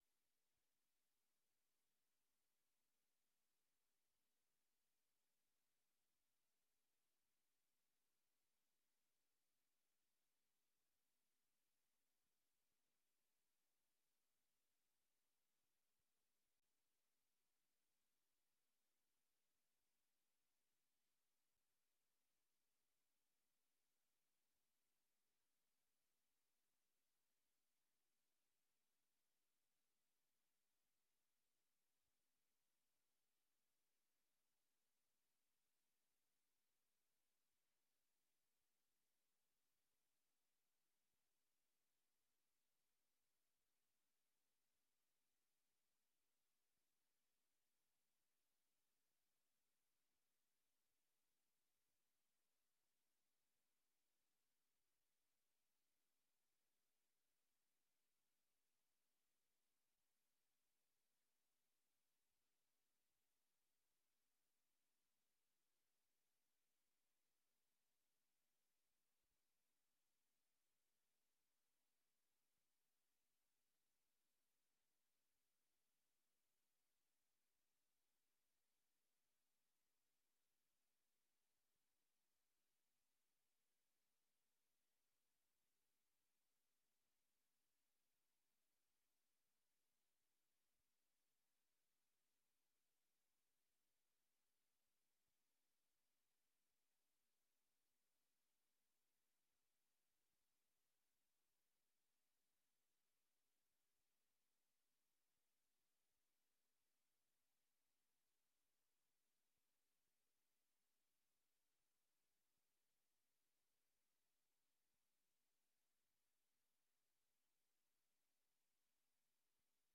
Beeldvormende vergadering 21 juni 2023 19:30:00, Gemeente Dronten
Download de volledige audio van deze vergadering